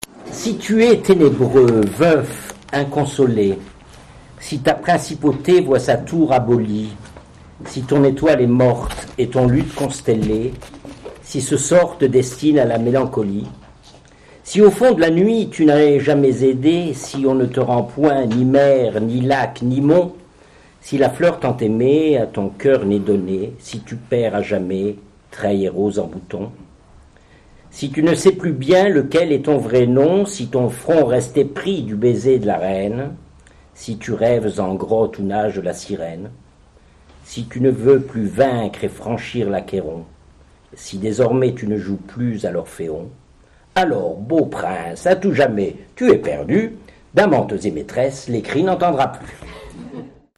Lecture publique donnée le samedi 27 octobre 2001 par